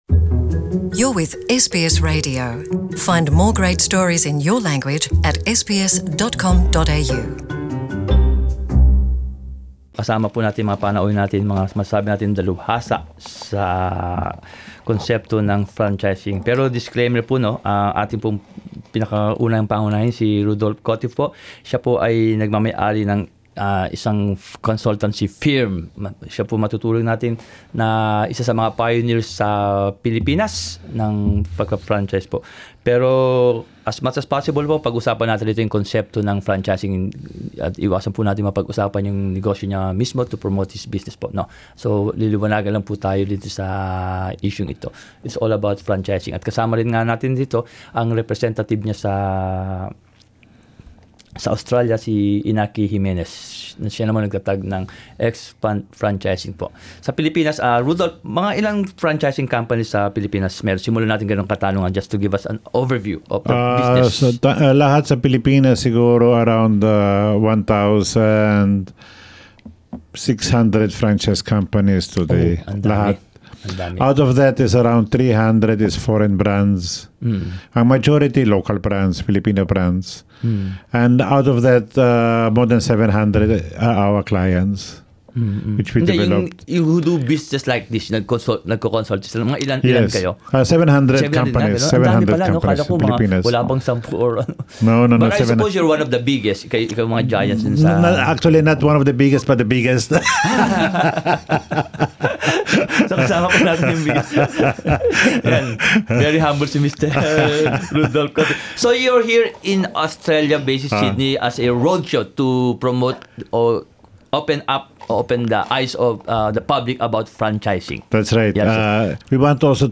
partner rito sa Australia ay pumunta sa SBS radio studio para talakayin ang mga oportunidad ng negosyo sa Pilipinas at Australya.